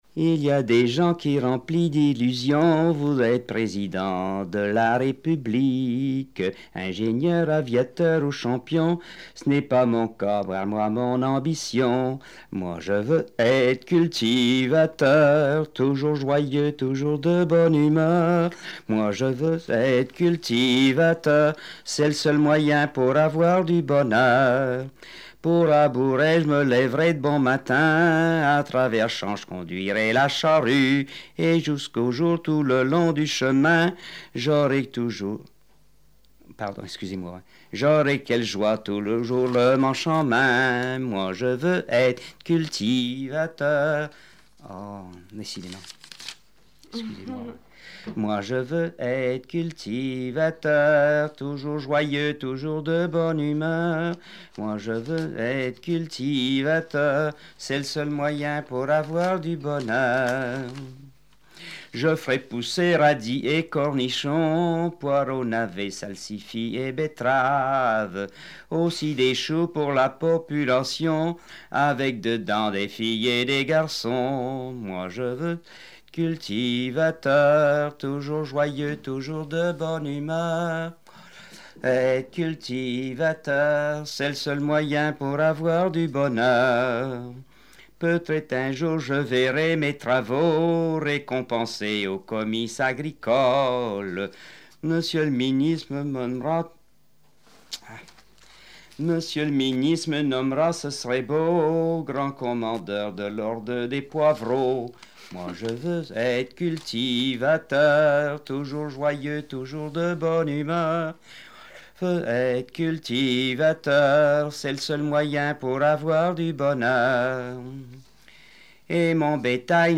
Barre-de-Monts (La)
Témoignages et chansons populaires et traditionnelles
Pièce musicale inédite